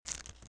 plastic_putdown.mp3